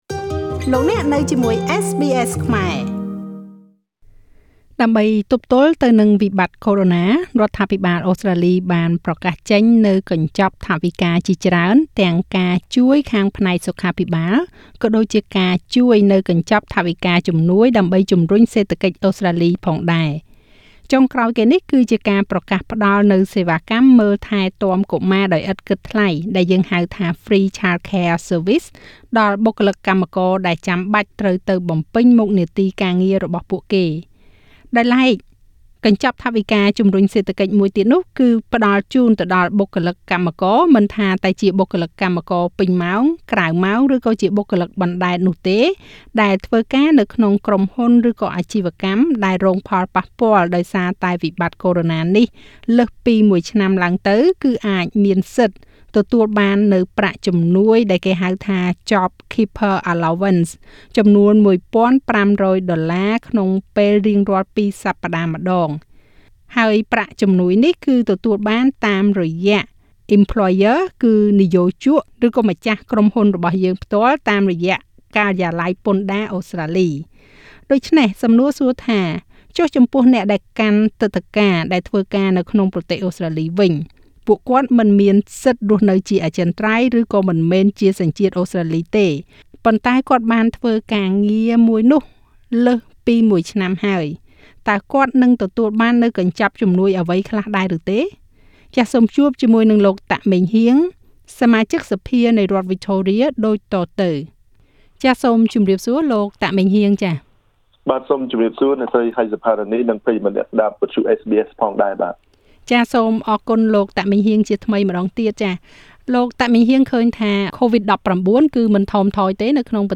ចុះចំពោះអ្នកកាន់ទិដ្ឋាការដែលធ្វើការលើសពីមួយឆ្នាំនៅក្នុងប្រទេសអូស្រ្តាលីវិញ តើមានទទួលបាននូវកញ្ចប់ជំនួយអ្វីខ្លះទេ? សូមជួបជាមួយនឹងលោក តាក ម៉េងហ៊ាង សមាជិកសភានៃរដ្ឋវិចថូរៀដូចតទៅ។